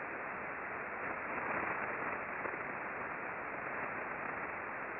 This was a night-time pass.
The receivers were connected to the antenna through a multicoupler. The antenna was an 8-element log periodic antenna pointed 120 degrees true (no tracking was used).
Short stereo sound files of the bursting are provided below the charts.